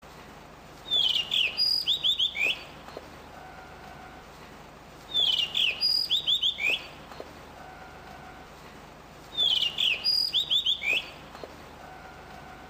コスモスを撮っていると、聞きなれない鳴き声がして、見上げると、
こんな鳴き声でした。